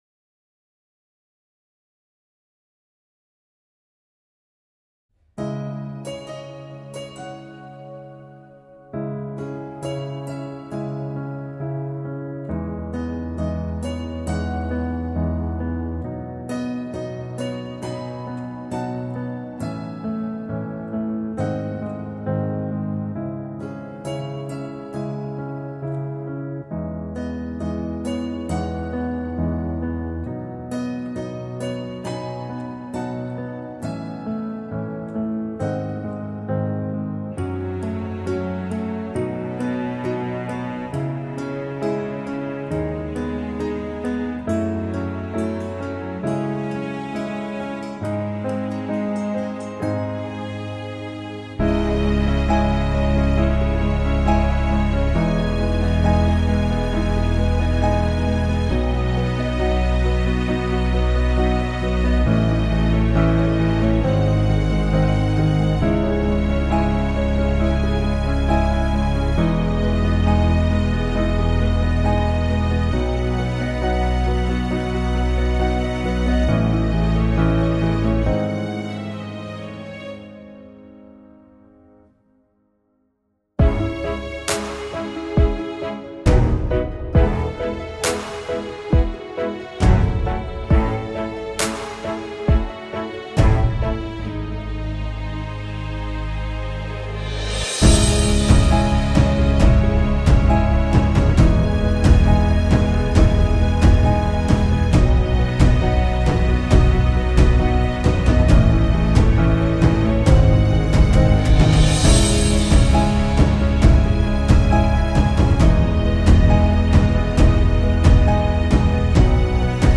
Una mezcla de clásica y electrónica
El acompañamiento: